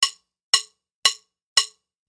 Звуки барабанных палочек
Ритмичные удары барабанных палочек